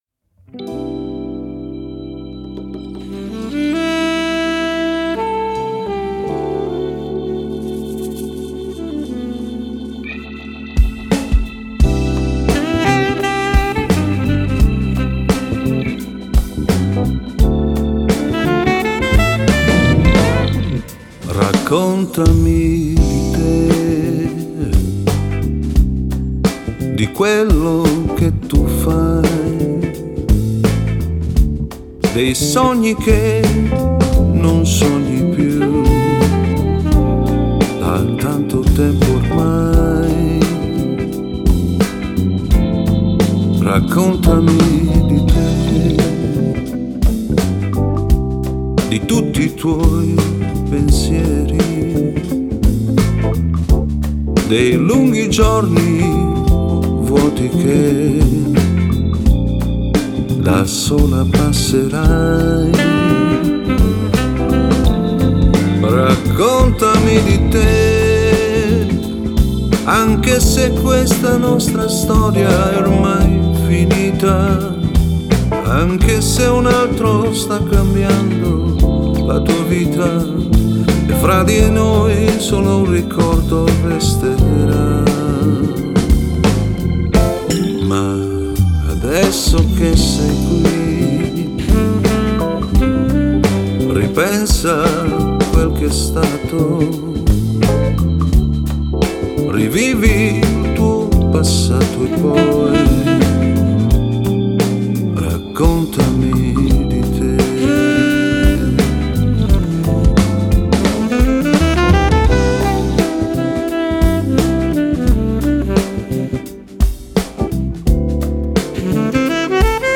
Genre: Pop Lounge, Bossa